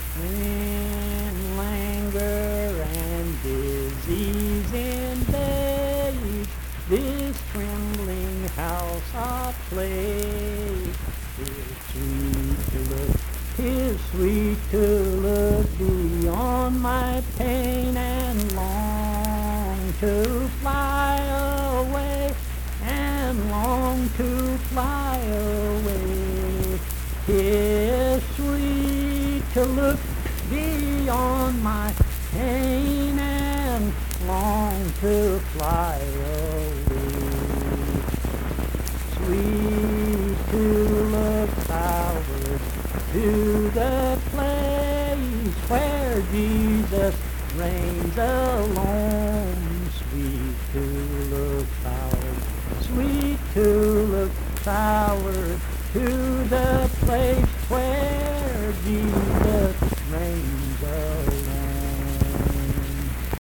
Accompanied (guitar) and unaccompanied vocal music
Performed in Mount Harmony, Marion County, WV.
Hymns and Spiritual Music
Voice (sung)